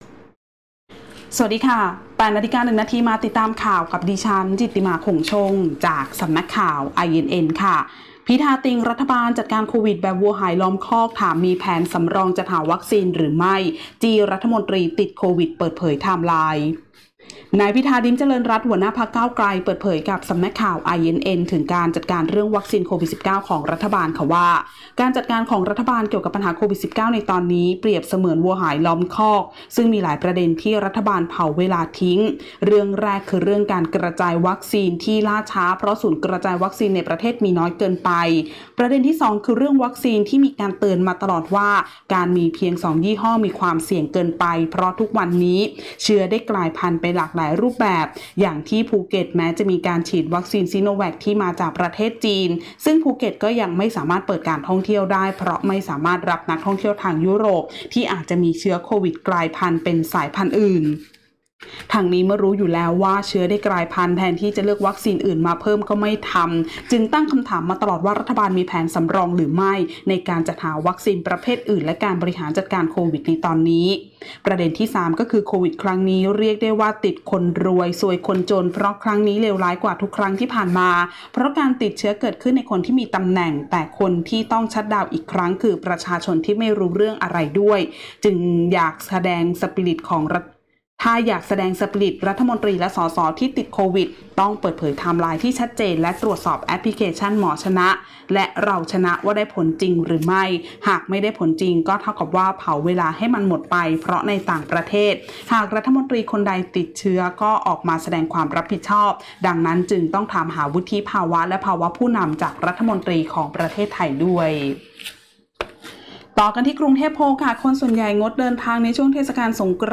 คลิปข่าวต้นชั่วโมง
ข่าวต้นชั่วโมง 08.00 น.